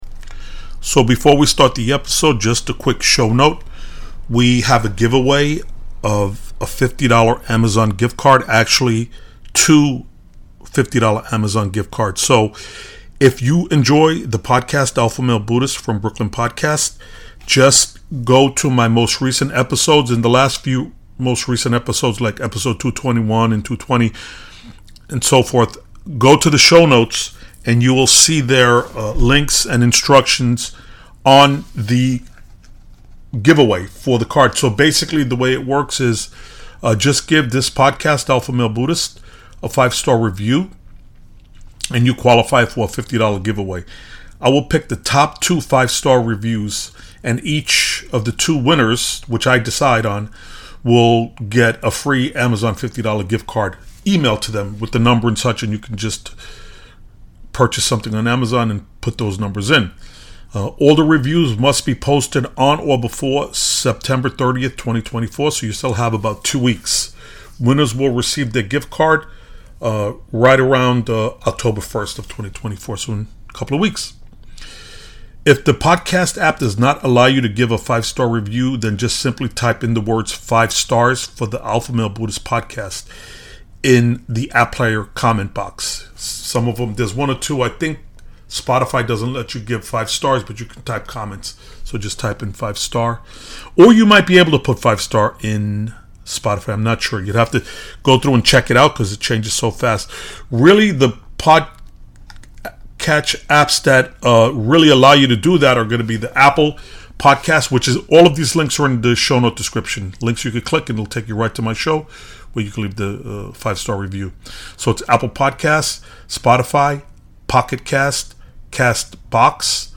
EP 24- Joe Rogan Podcast- Be the Hero of your own movie- Motivational speech- How to live your dreams- take action- no excuses-